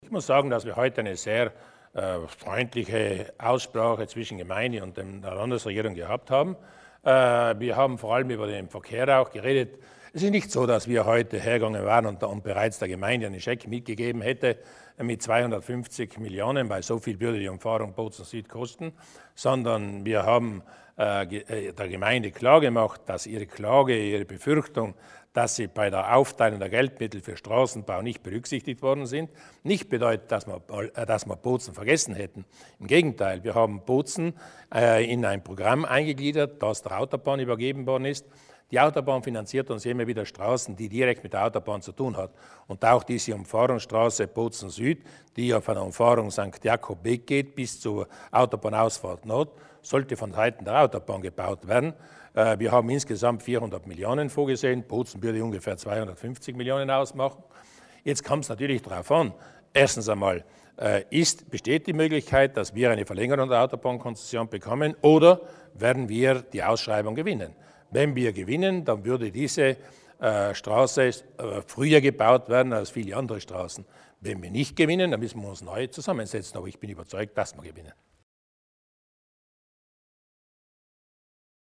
Landeshauptman Durnwalder erläutert die Projekte für die Stadt Bozen